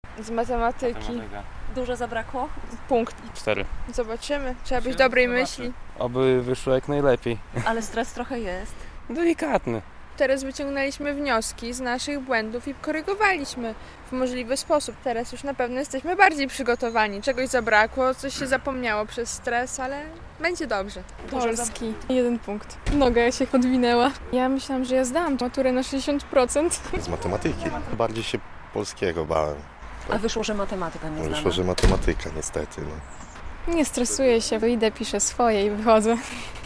Maturzyści piszą egzaminy poprawkowe - relacja